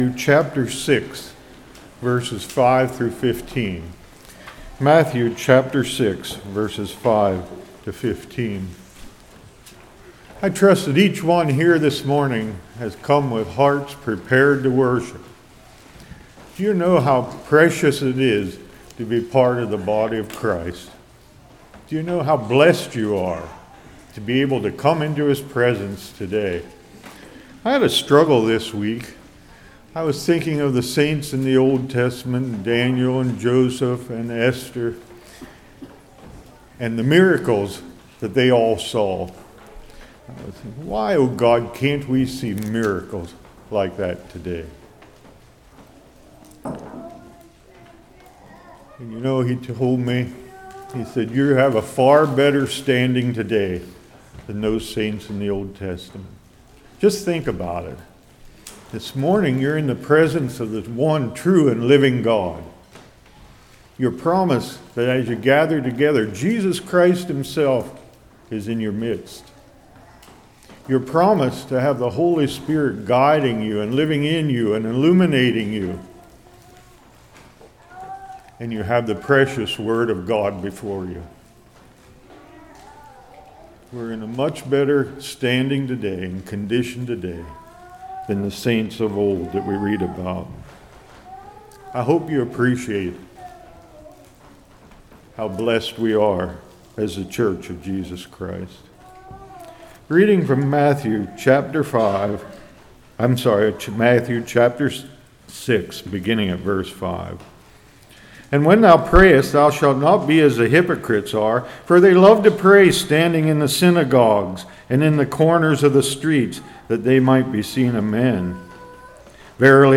Matthew 6:5-15 Service Type: Morning Why Pray How Not To Pray How to Pray « Oh